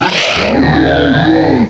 cry_not_probopass.aif